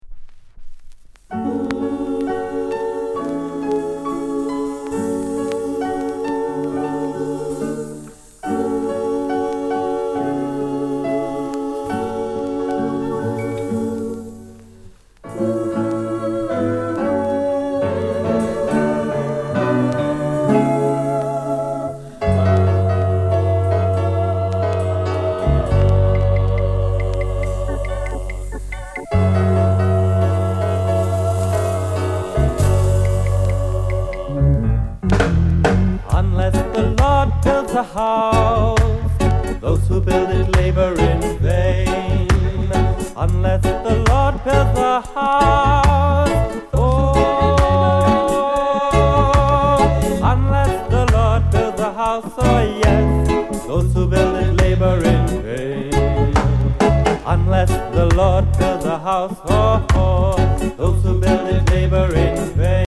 ROOTS